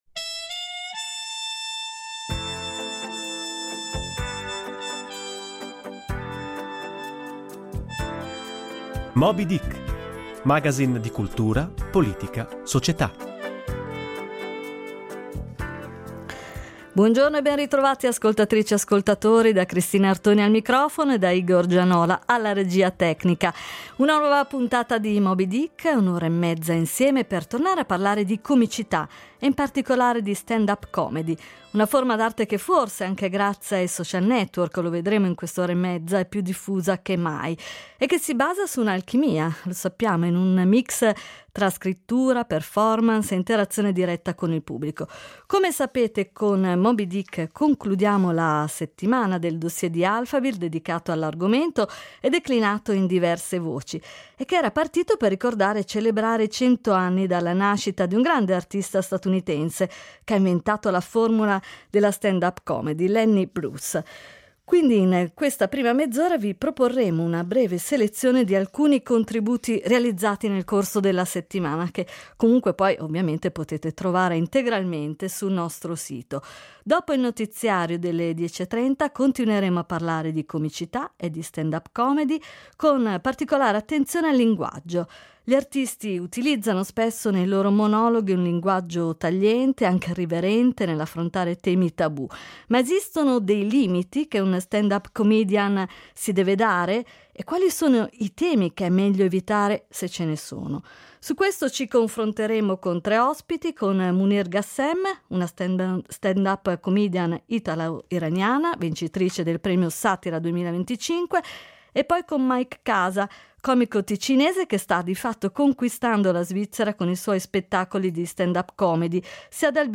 1 Episodio Sedicesimo: Réclame Live alla Comedy Week. 1:19:25
1 Episodio Sedicesimo: Réclame Live alla Comedy Week. 1:19:25 Play Pause 13d ago 1:19:25 Play Pause Riproduci in seguito Riproduci in seguito Liste Like Like aggiunto 1:19:25 Cosa succede quando i comici vogliono fare la pubblicità? E quando i pubblicitari vogliono far ridere? Abbiamo chiuso la Comedy Week con una puntata speciale live all'Osteria Democratica di Milano, cercando di rispondere a queste domande con un viaggio nel tempo che parte con i Caroselli di Marchesi e arriva al Tavernello di Maccio Capatonda.